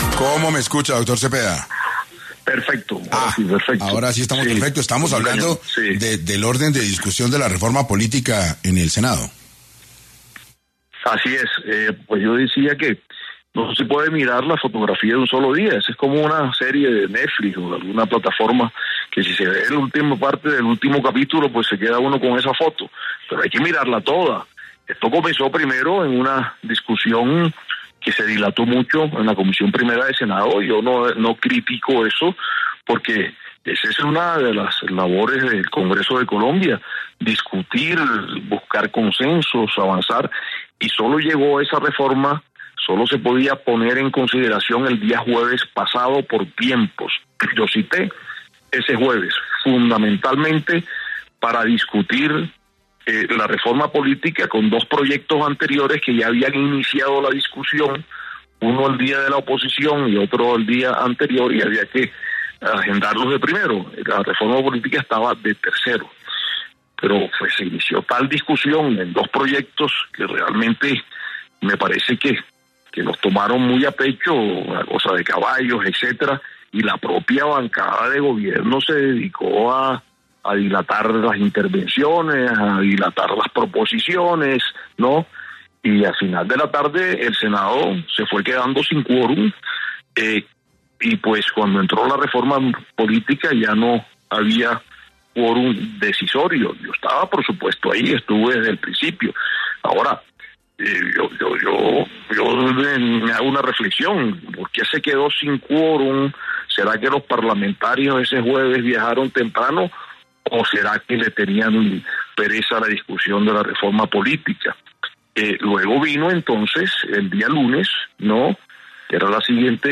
En Caracol Radio estuvo Efraín Cepeda, presidente del Senado